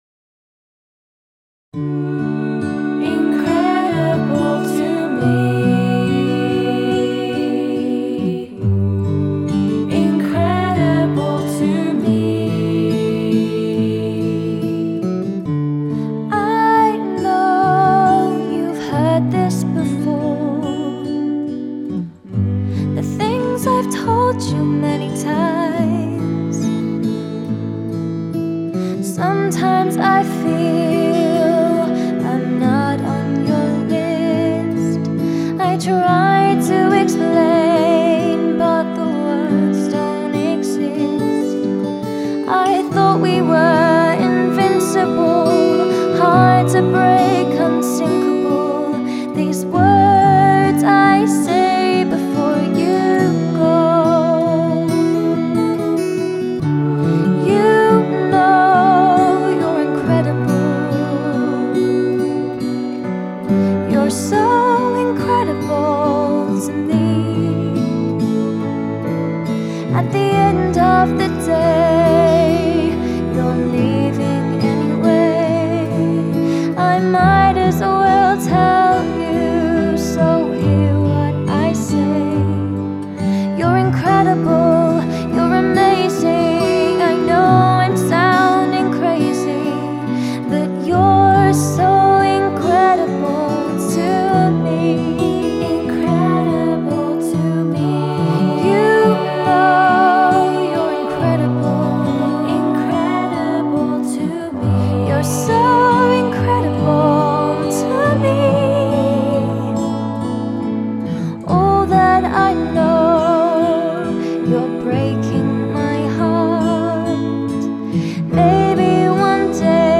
Genre: Ballad